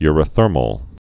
(yrə-thûrməl) also eu·ry·ther·mic (-mĭk) or eu·ry·ther·mous (-məs)